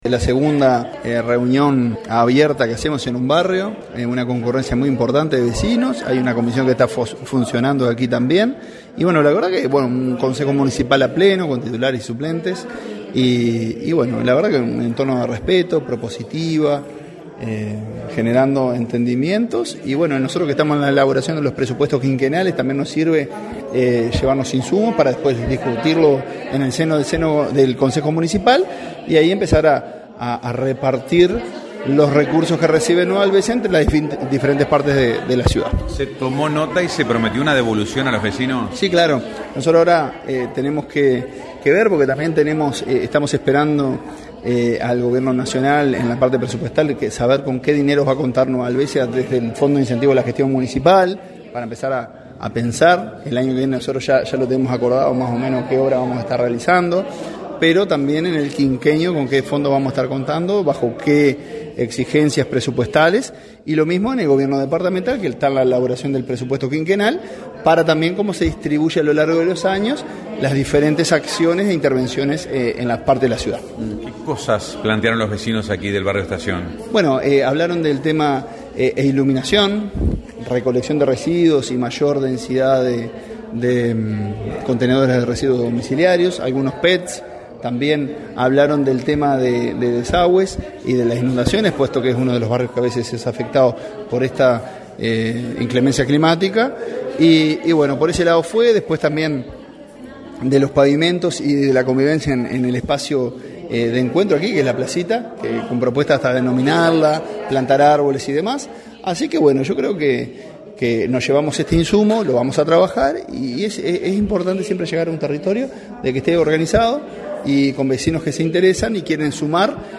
Tras el encuentro, dialogamos con el alcalde Marcelo Alonso.